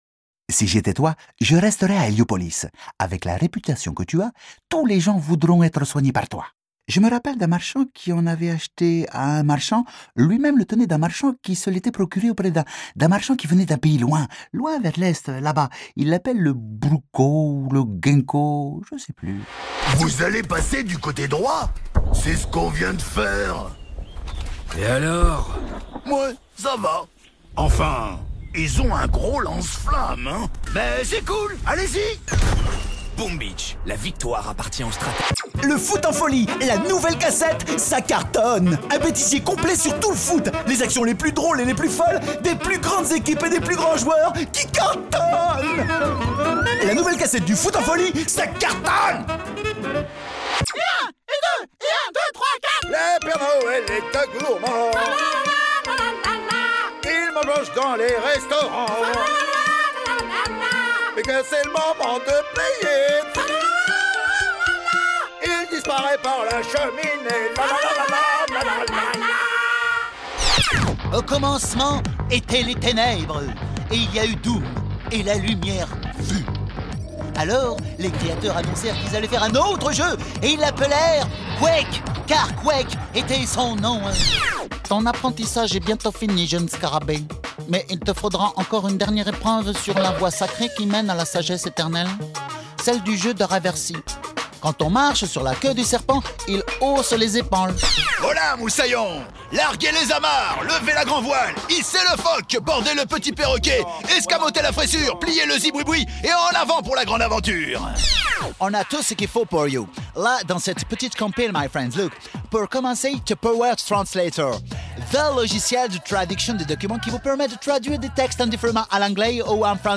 Voix off
Medley de différent cartoons qui ont été difusé.
Aimable, affirmé, autoritaire, didactique, sérieux, paternel, grave, rassurant,
- Baryton-basse Baryton